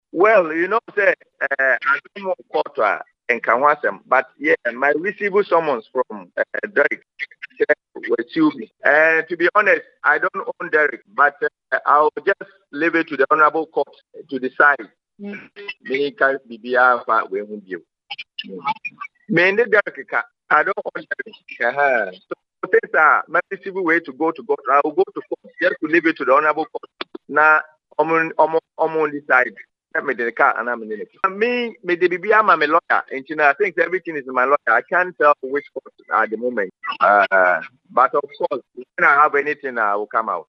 “Well, you know that the matter is in court and when an issue is in court you don’t talk about it,” Paintsil said in an interview with Kasapa FM.
Listen to John Paintsil deny owing Derek Boateng $20,000 below;